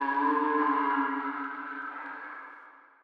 HeyVox.wav